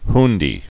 (hndē)